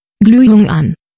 Diesen Logschen Schalter dann in Sprachausgabe verwendet um Sprachausgabe für Glühmeldung zu geben.